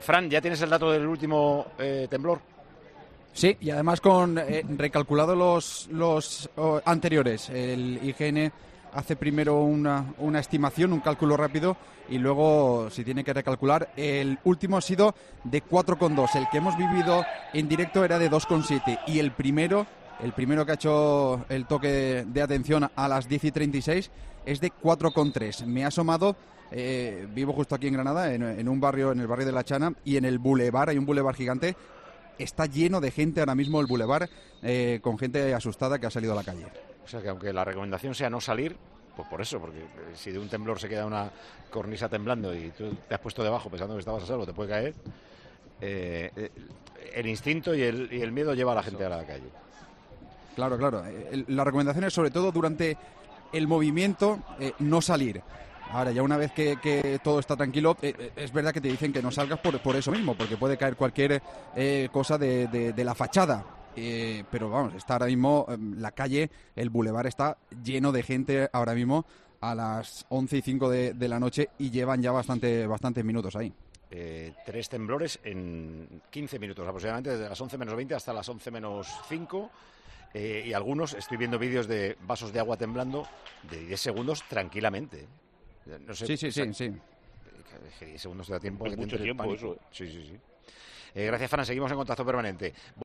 cuenta en 'Tiempo de Juego' cómo se han sentido los terremotos